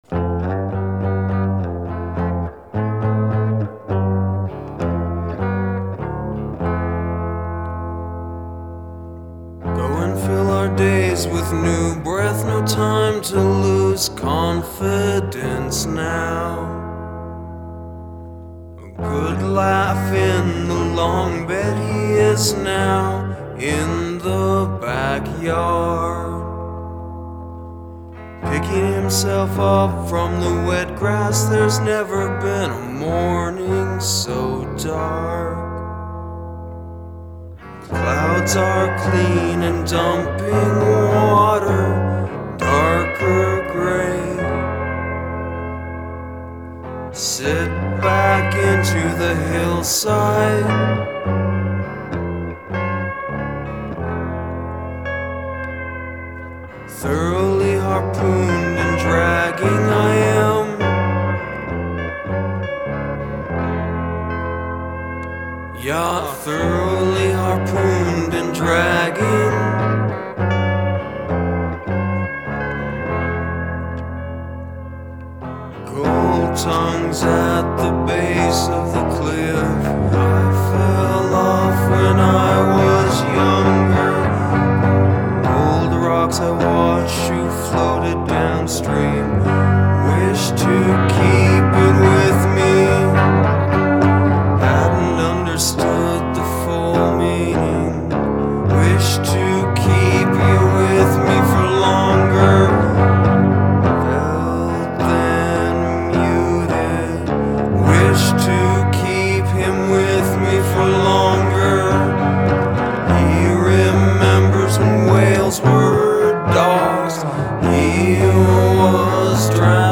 Whoah, getting kind of grungey.
You’ll notice most of these songs only have one riff.